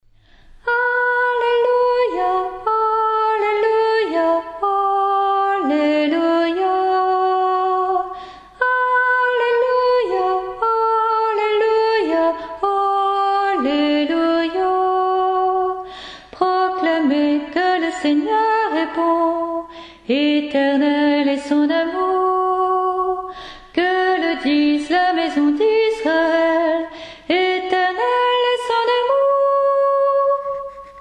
Voix chantée (MP3)COUPLET/REFRAIN
SOPRANE